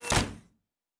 pgs/Assets/Audio/Sci-Fi Sounds/Doors and Portals/Door 6 Close.wav at 7452e70b8c5ad2f7daae623e1a952eb18c9caab4
Door 6 Close.wav